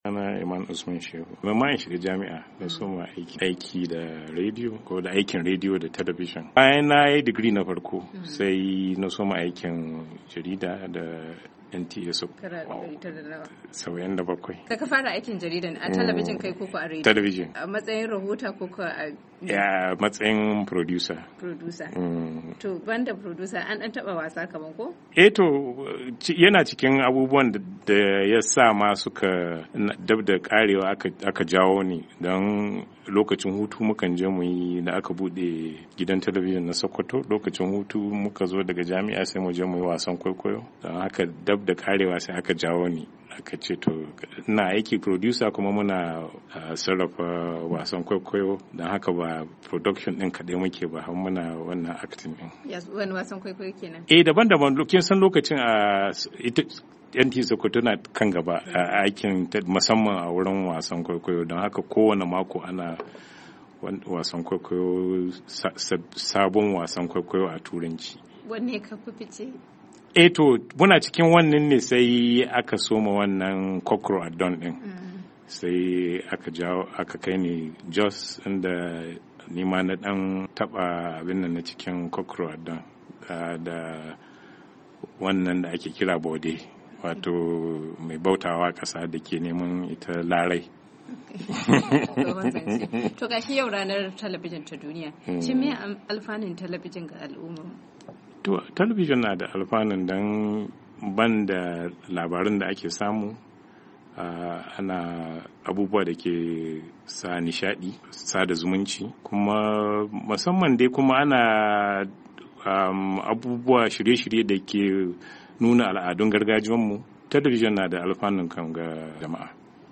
Ga cikakken rahoton